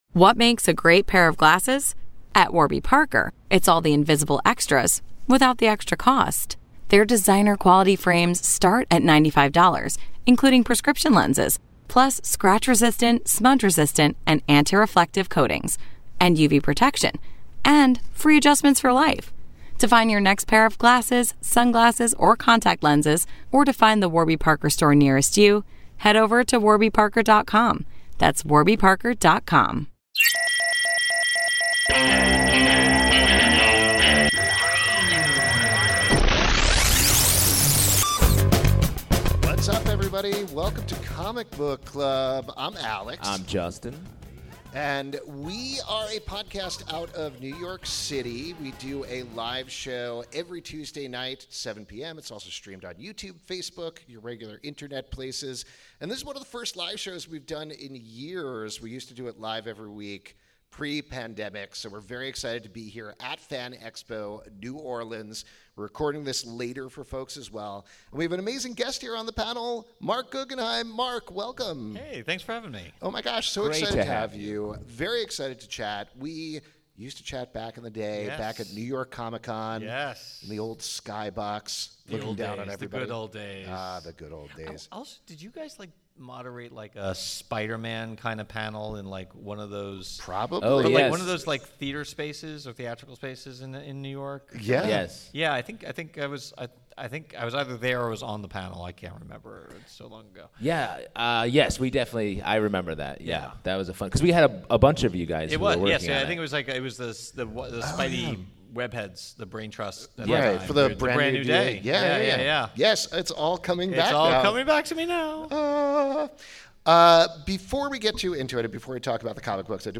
Comic Book Club Live At Fan Expo New Orleans With Marc Guggenheim